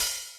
Drums_K4(11).wav